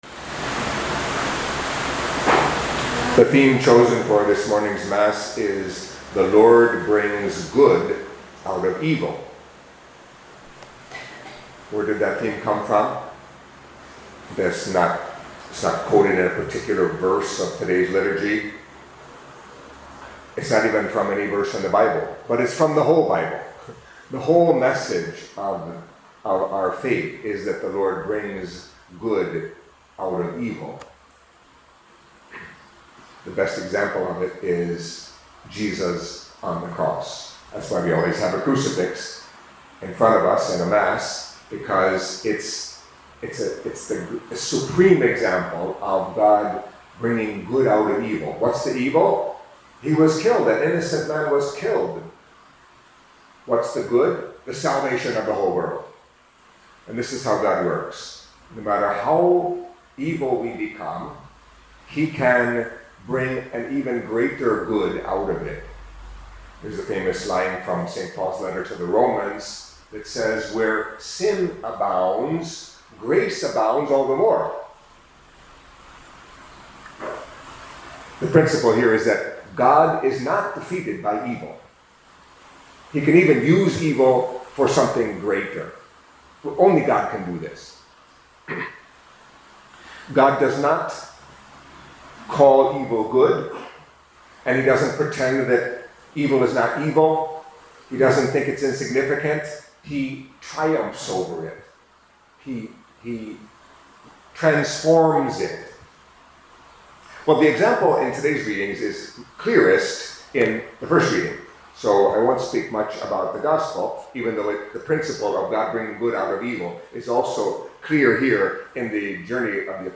Catholic Mass homily for Saturday of the Fourteenth Week in Ordinary Time